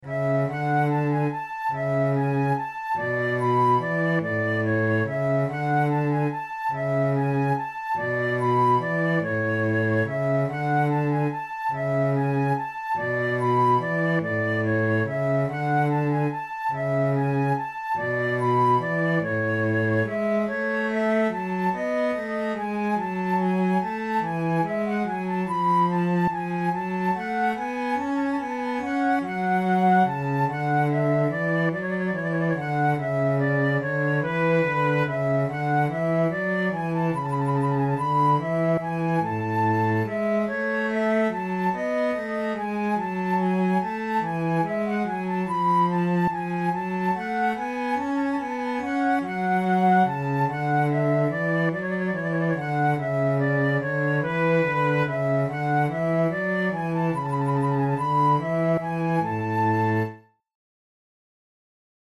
KeyA major
Time signature3/8
Tempo144 BPM
Baroque, Passepieds
6.Passepied II Flute & bass